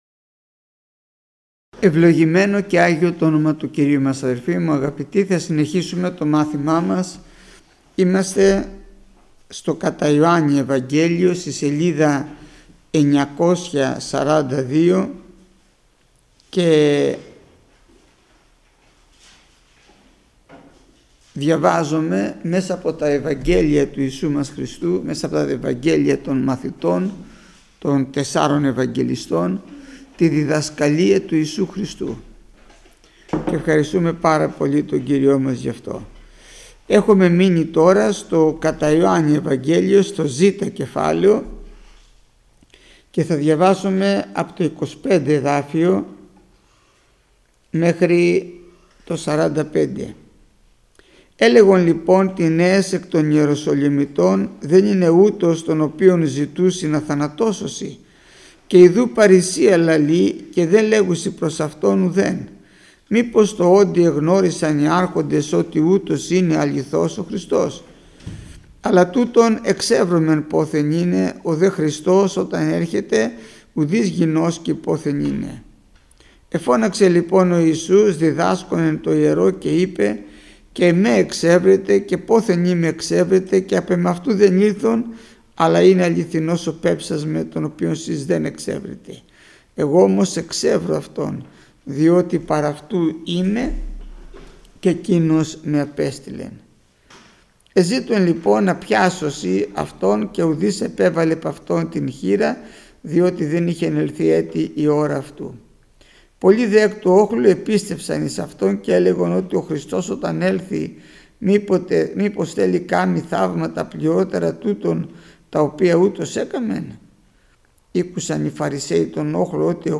Μάθημα 499 Γεννηθήτω το θέλημά σου